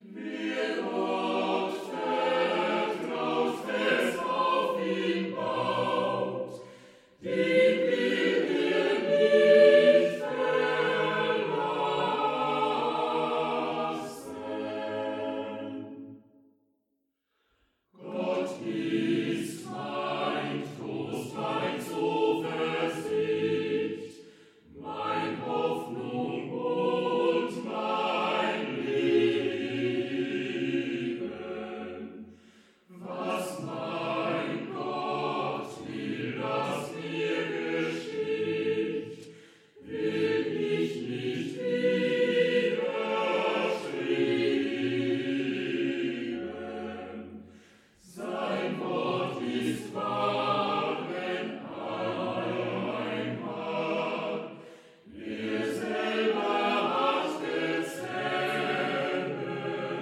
15 mutmachende Choräle
Choral
Chormusik-CD